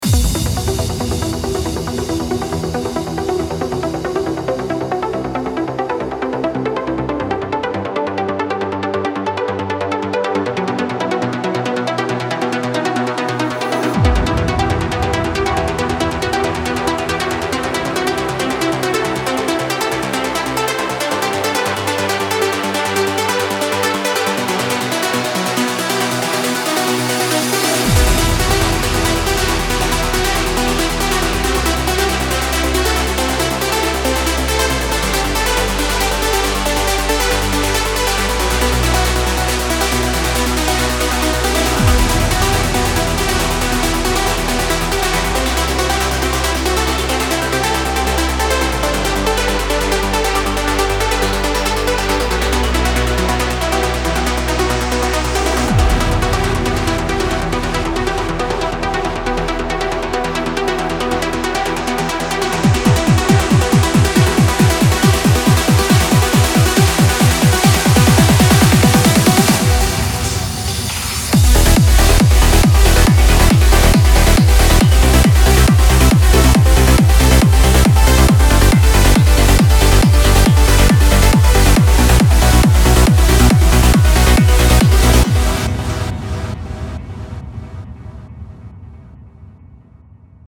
Демка на оценку
Это же транс.